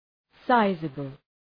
Προφορά
{‘saızəbəl}